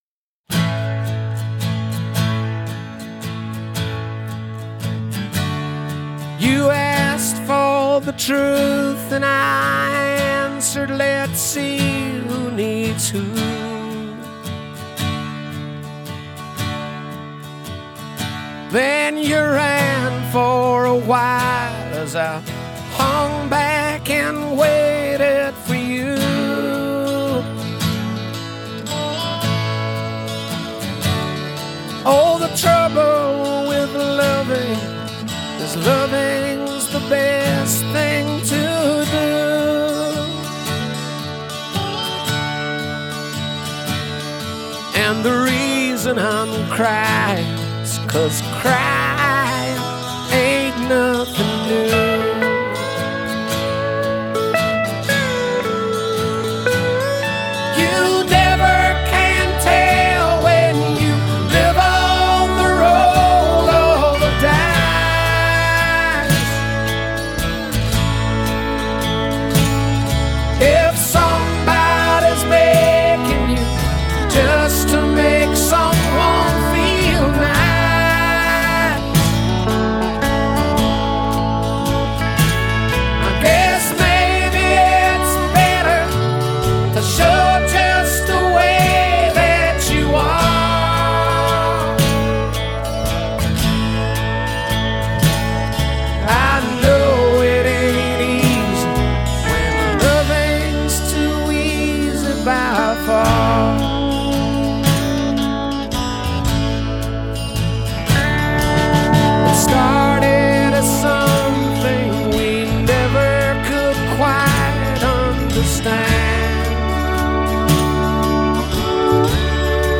Rock.